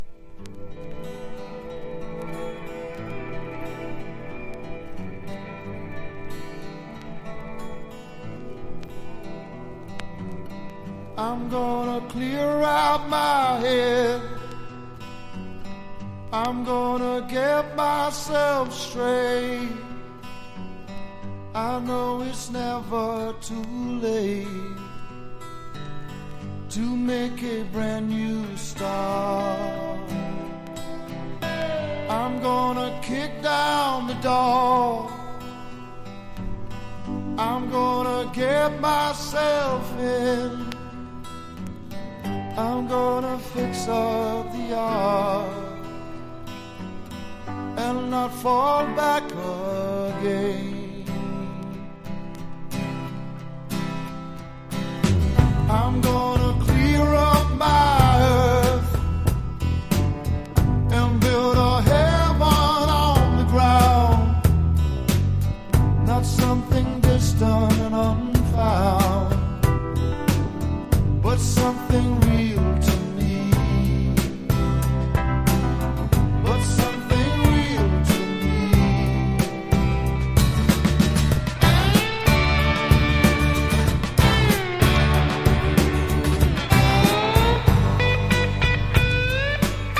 NEO ACOUSTIC / GUITAR POP (90-20’s)